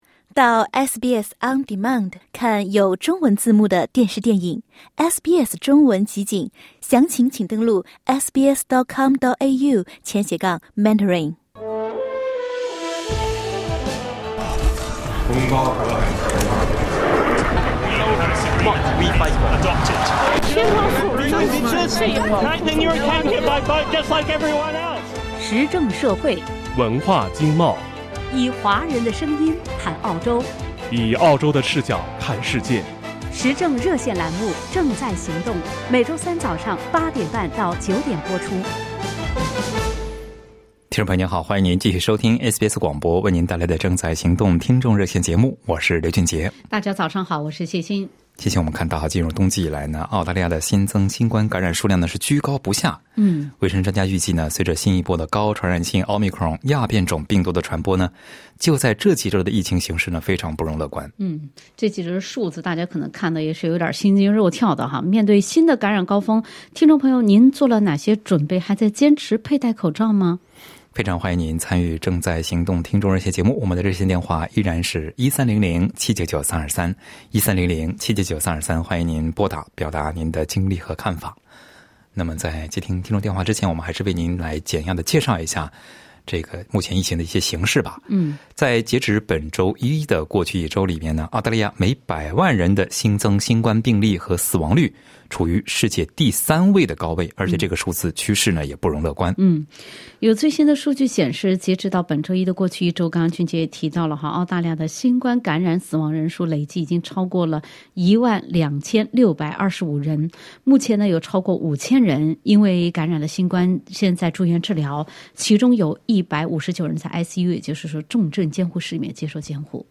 请您点击收听本期《正在行动》听众热线节目的完整内容。